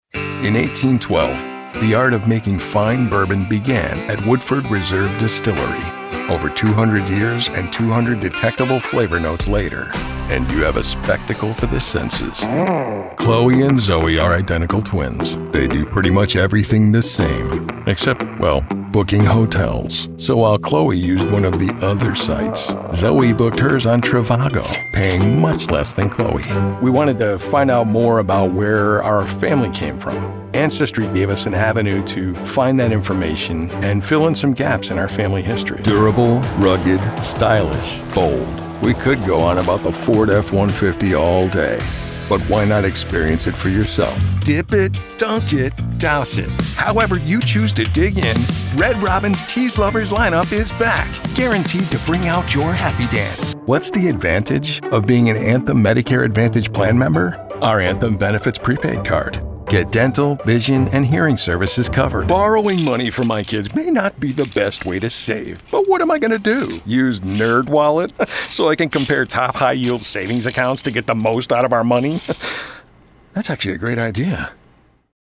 Confident Gen X male voice for your project – the casual, conversational sound to complete that commercial, co...
Conversational
Confident
Casual